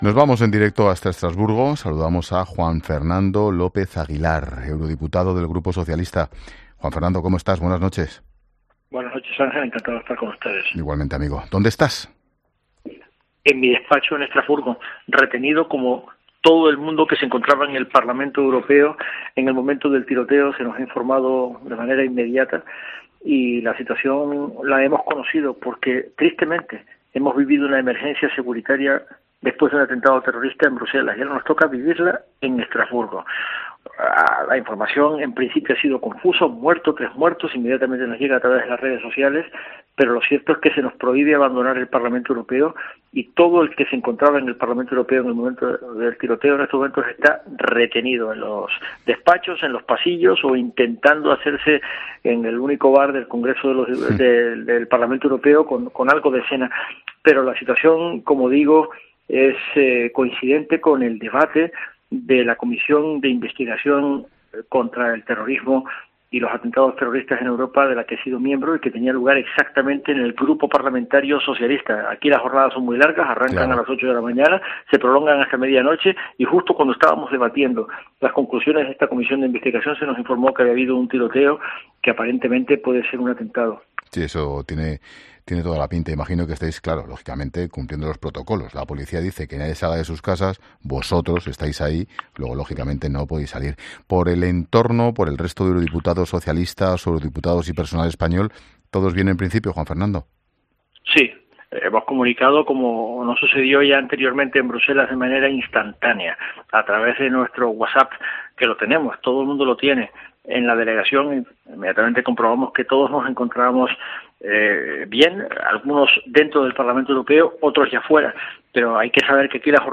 Juan Fernando López Aguilar, diputado del PSOE en el Parlamento Europeo, también atendió la llamada de 'La Linterna' desde Estrasburgo para informar de la última hora del tiroteo.